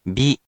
We’re going to show you the character, then you you can click the play button to hear QUIZBO™ sound it out for you.
In romaji, 「び」 is transliterated as 「bi」which sounds like the letter 「B」or 「bee」in more of an American accent.